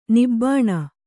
♪ nibbāṇa